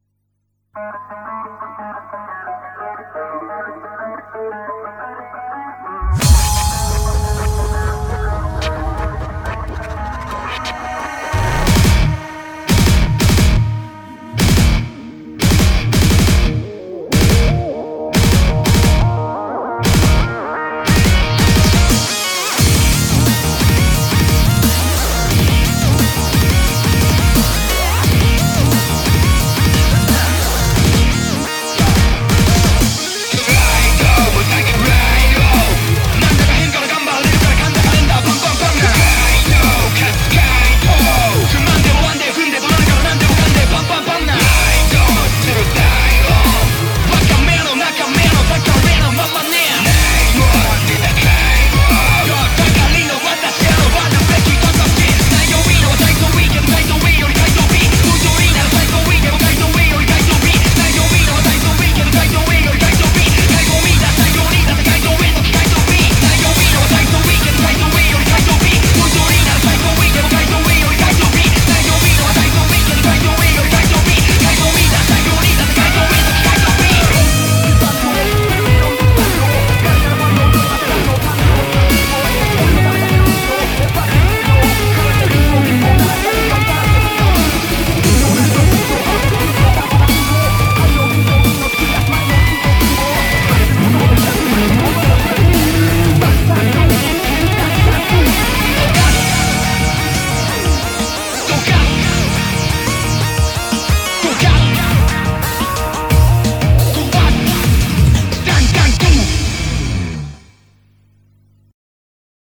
BPM88-176
Audio QualityPerfect (Low Quality)